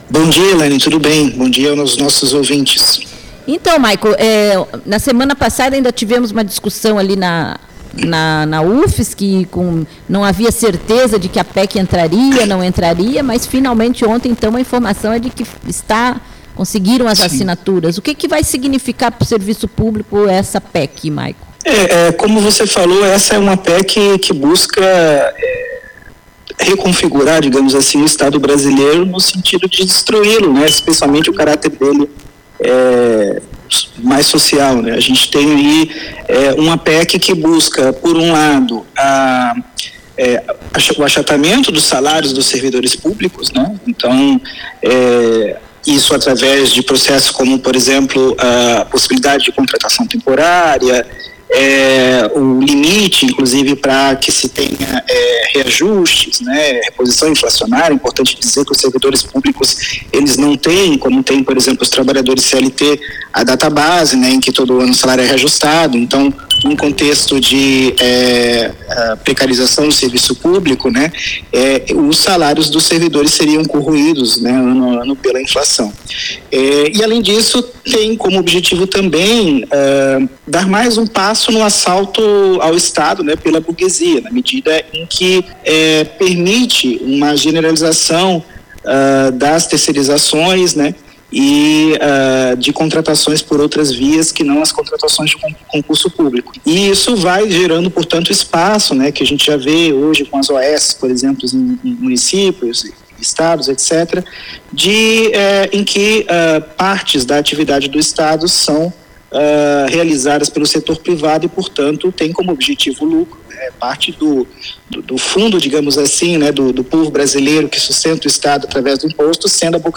falou sobre o tema no programa Campo de Peixe.